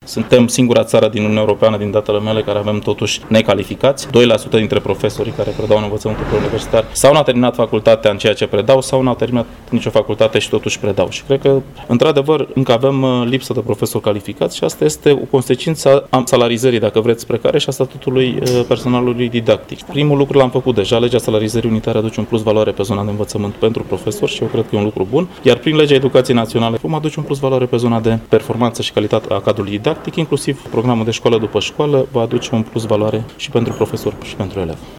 Afirmația îi aparține ministrului Educației, Liviu Pop, prezent la festivitatea de absolvire a celei de a patra serii de elevi ai Scolii Profesionale Germane Kronstadt, de la Braşov.
Legat de acest ultim aspect, ministrul Liviu Pop a oferit o declaraţie surprinzătoare: